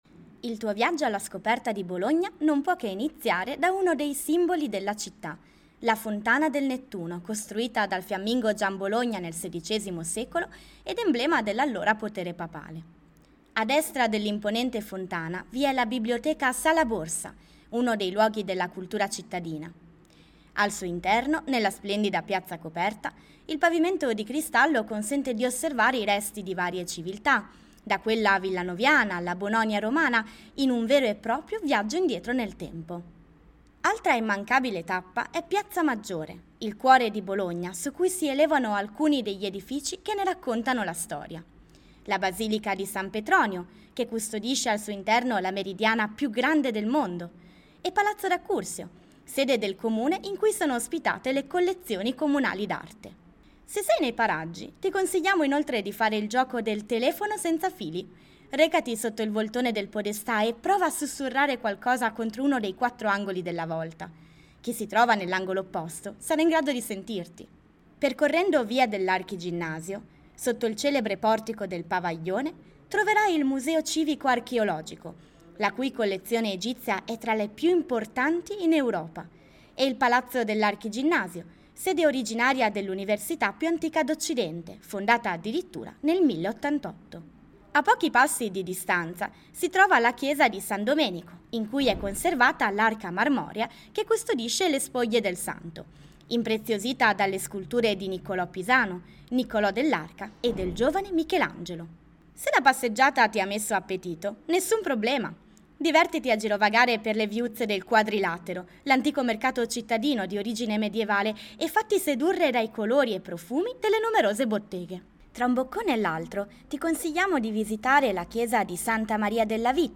Audioguida - Bologna Magica: maghi, diavoli e stregonerieDownload